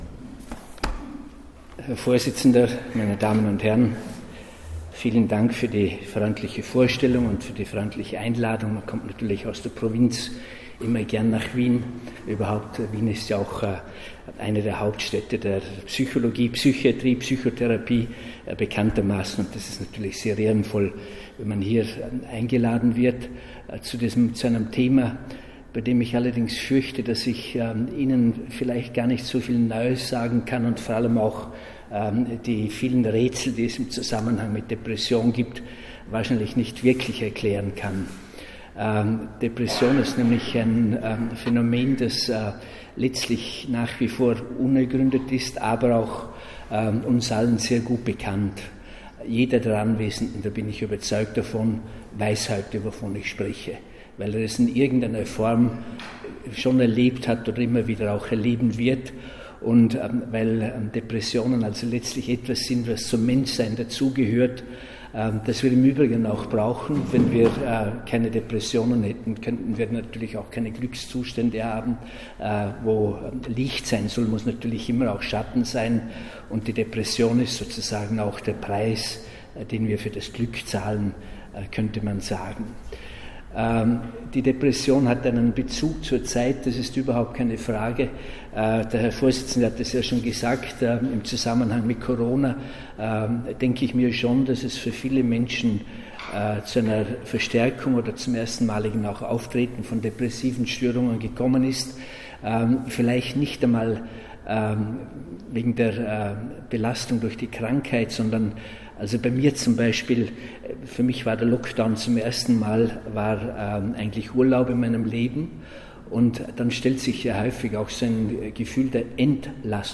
Am 9. Oktober sprach Prim. Univ.-Prof. Dr. med. Reinhard Haller zum Thema:
Sie können den ersten Teil seines Vortrags hier nachhören